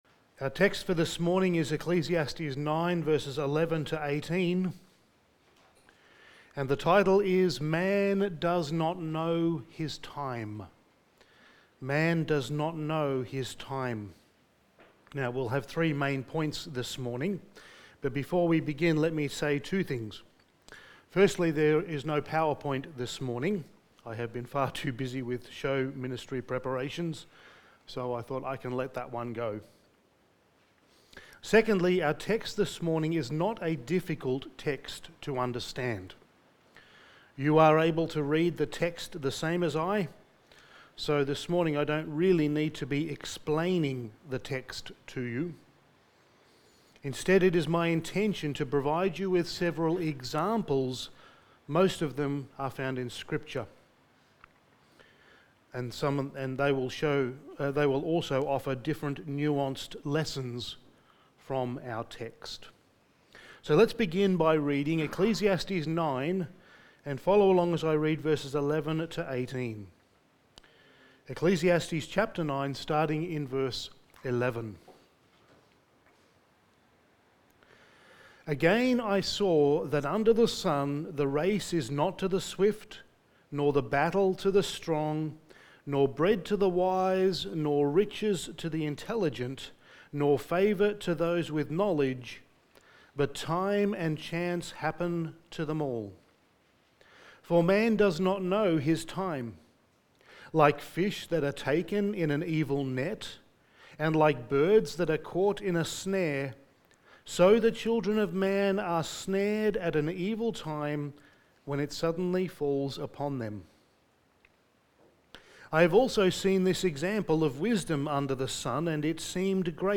Studies in the Book of Ecclesiastes Sermon 19: Man Does Not Know His Time
Service Type: Sunday Morning